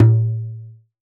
Hand Hide Drum 01.wav